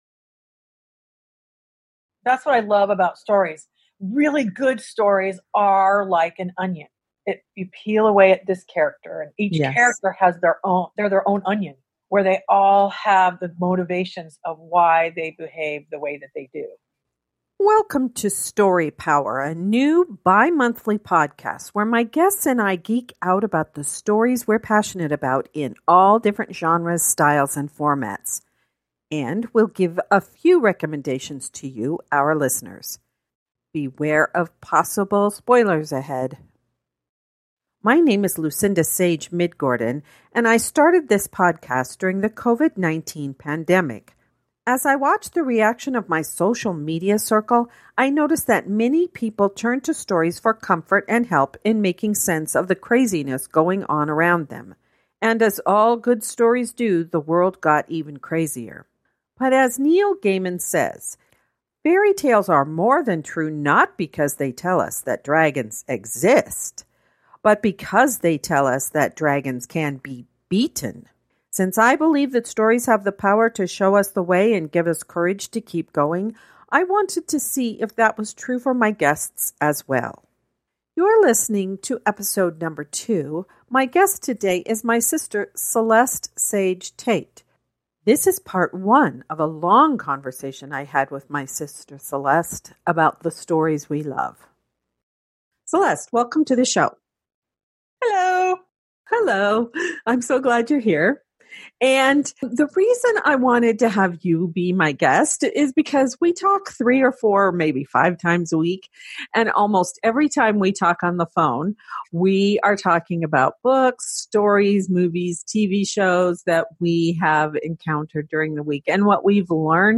This is the first of an edited two hour conversation I had with my sister